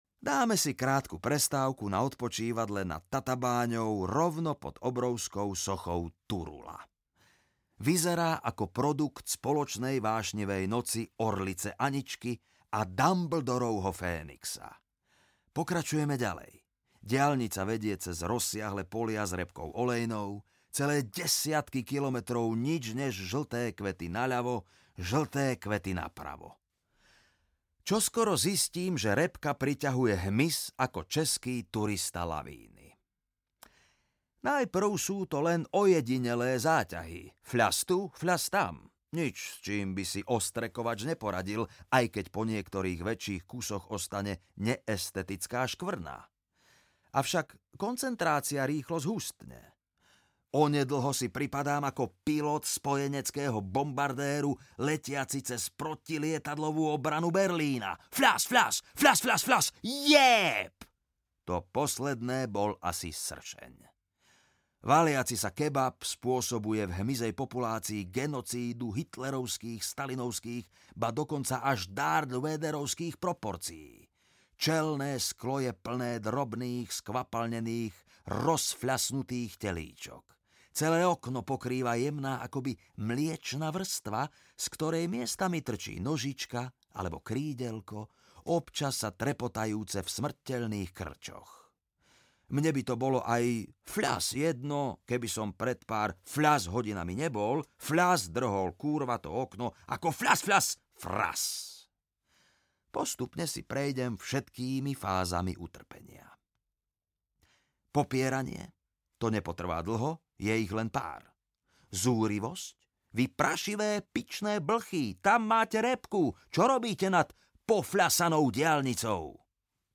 Ako som vozil Kórejcov audiokniha
Ukázka z knihy